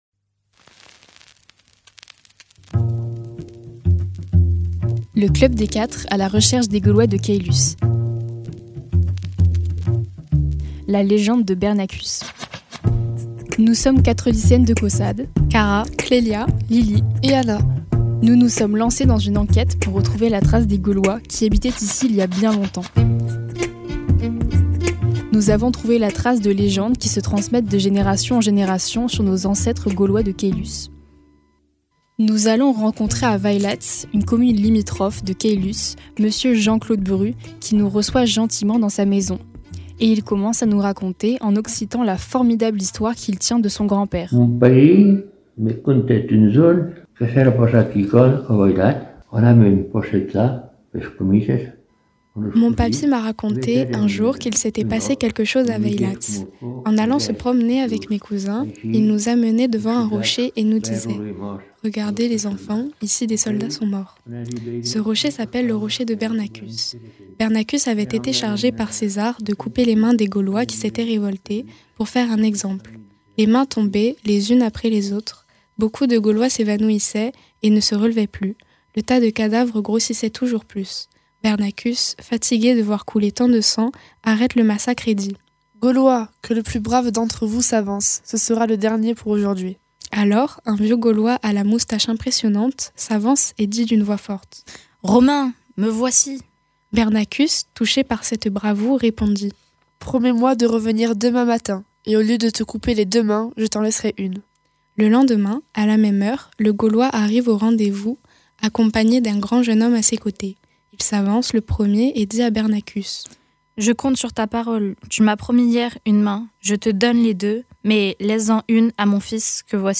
Interviews
Nous nous chargeons de la traduction. Il s’agit de la légende de l’officier romain chargé par César de punir les guerriers gaulois d’Uxellodunum en leur coupant les mains.